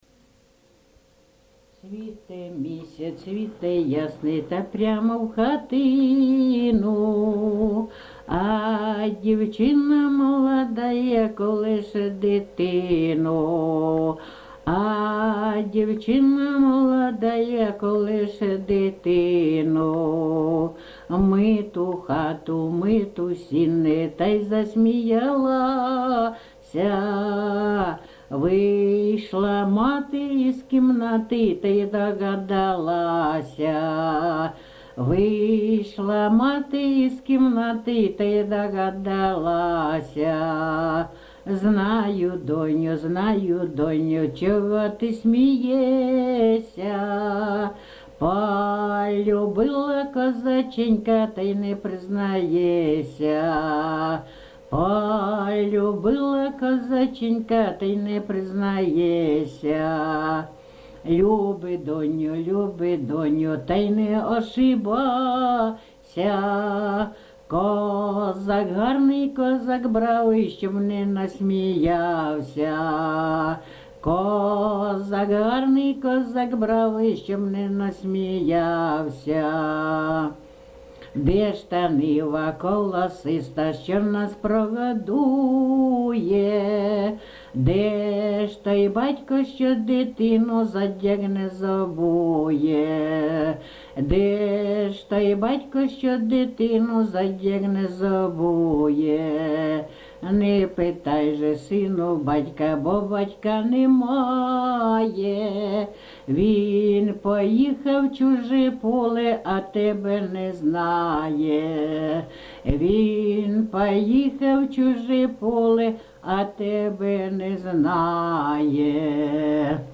ЖанрПісні з особистого та родинного життя
Місце записус. Некременне, Олександрівський (Краматорський) район, Донецька обл., Україна, Слобожанщина